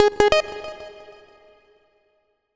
フリー効果音：起動
フリー効果音｜ジャンル：システム、目覚めっぽいデジタルなシステム効果音です！
wakeup.mp3